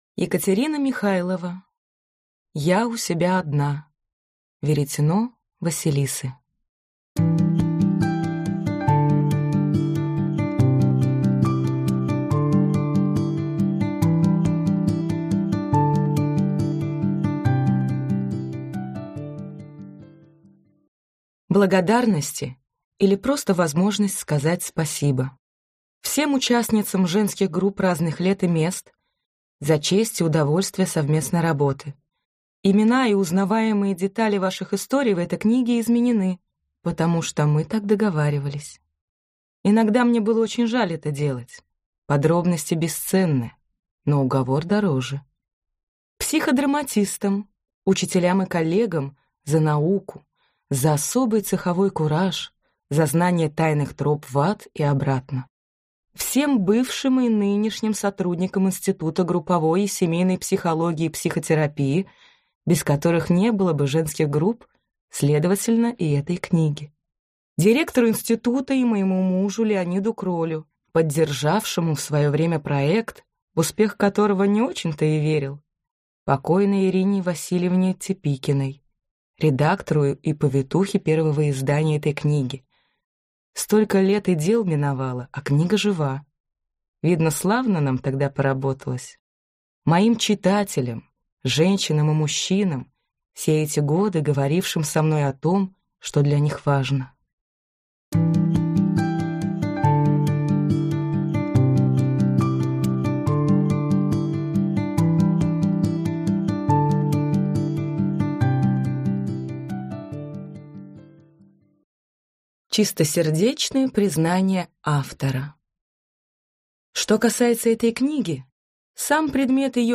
Аудиокнига Я у себя одна, или Веретено Василисы | Библиотека аудиокниг